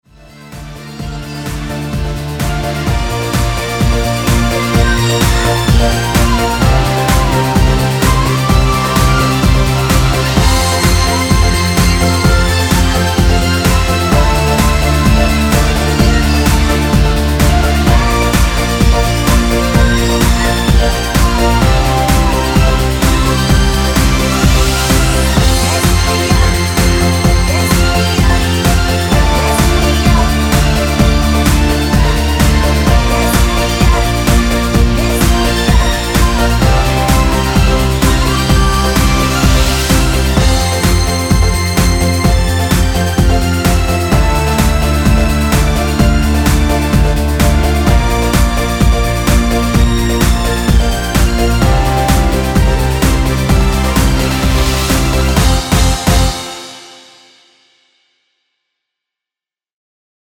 엔딩이 페이드 아웃이라 엔딩을 만들어 놓았습니다.(원키 코러스 MR 미리듣기 확인)
원키에서(+3)올린 코러스 포함된 MR입니다.
앞부분30초, 뒷부분30초씩 편집해서 올려 드리고 있습니다.
중간에 음이 끈어지고 다시 나오는 이유는